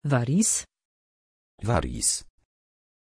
Pronunciation of Waris
pronunciation-waris-pl.mp3